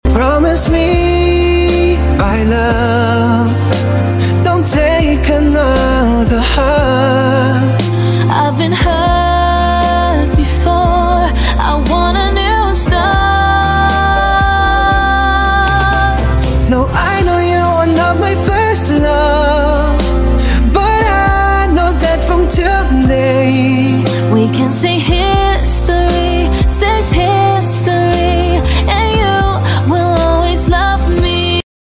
Beautiful music